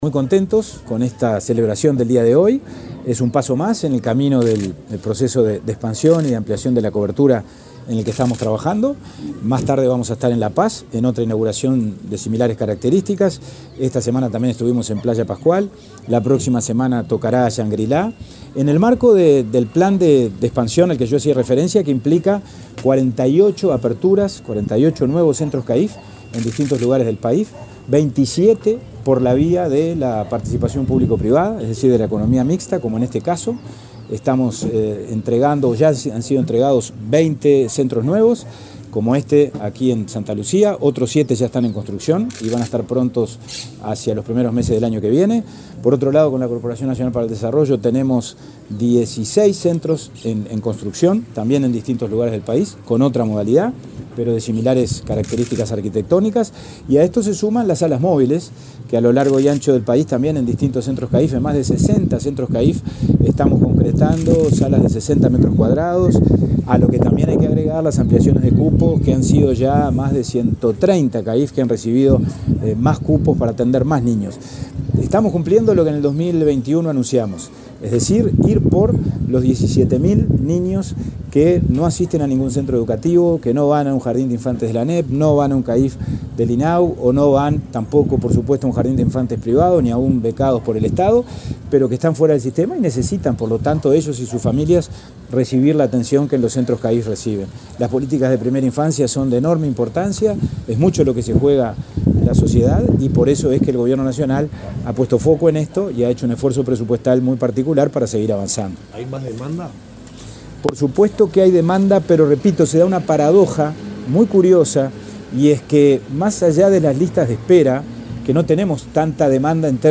Declaraciones del presidente de INAU, Pablo Abdala
El presidente de Instituto del Niño y el Adolescente del Uruguay (INAU), Pablo Abdala, dialogó con la prensa, luego de inaugurar un centro de atención